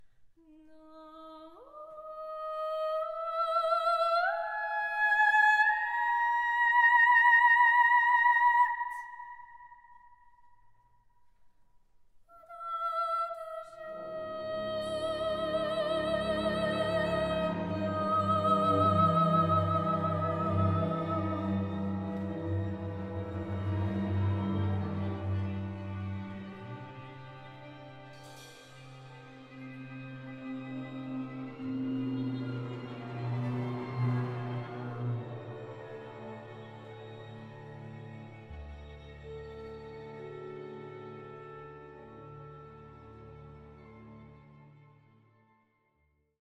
sopran koloraturowy
glos-probka.mp3